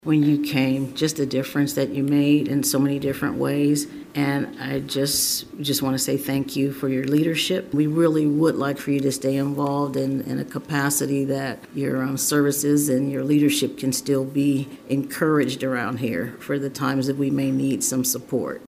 After three years and one month leading the Riley County Police Department, Riley County Police Department Director Dennis Butler sat in on his final Law Board meeting Tuesday afternoon at Manhattan City Hall.